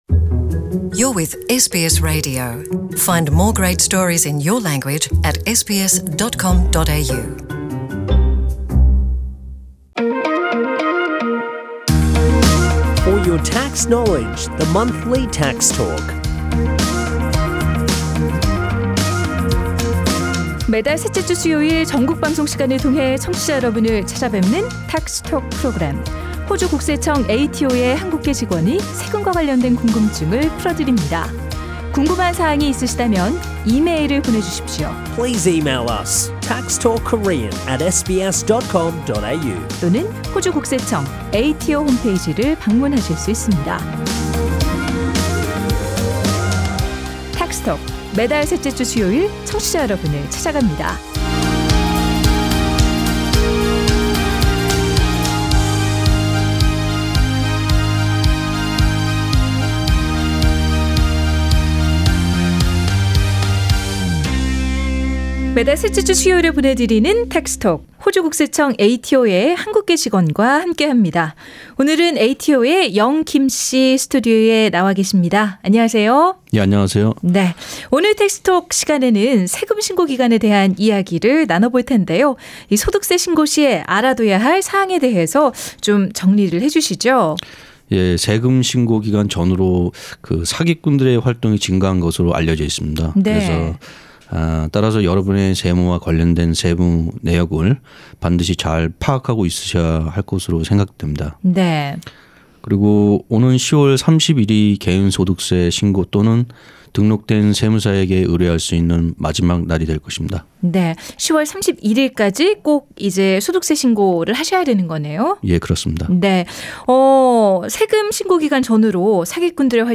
ATO’s employee with Korean background joins SBS radio studio to explain about tax time and scam alerts.